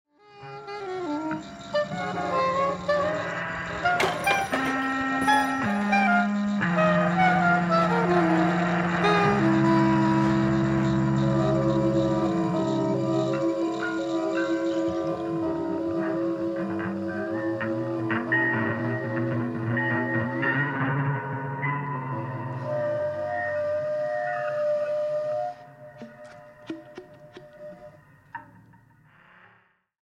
soprano & tenor saxophones, looper, electronics
keyboards
electric bass, electronics
drums, modular synthesizer